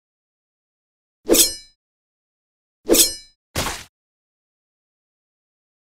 دانلود آهنگ ضربه شمشیر از افکت صوتی اشیاء
جلوه های صوتی